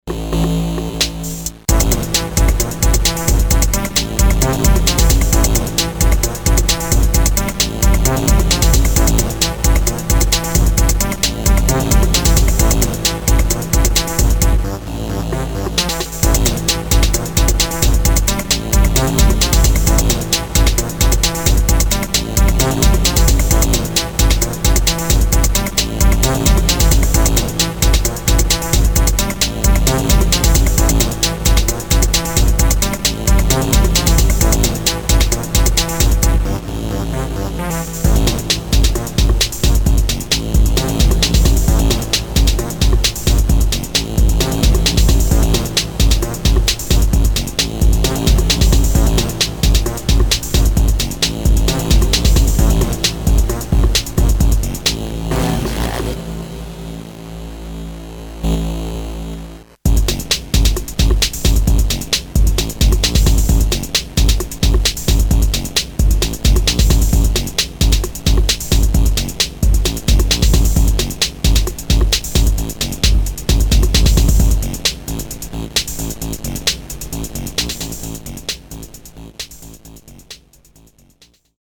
raw, analoque soundtrack
Disco Electro